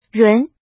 rún
run2.mp3